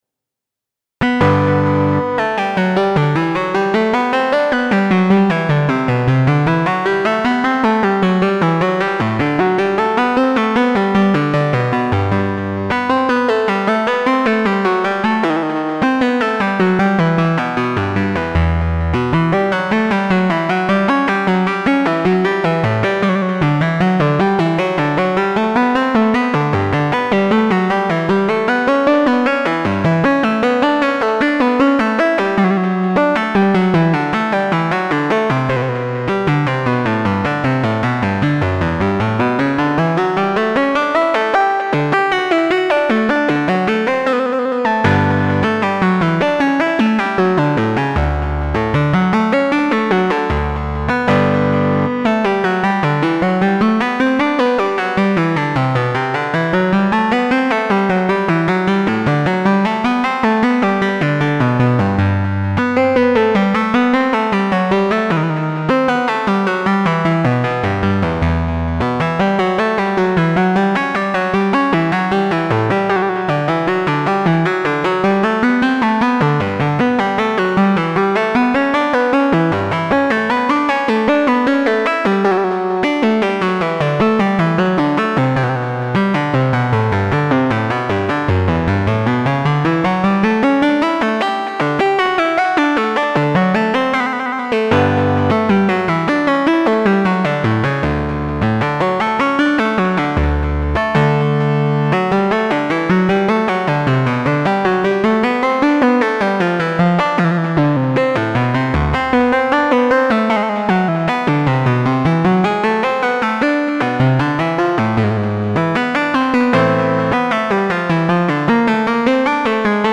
Bach Synthesizer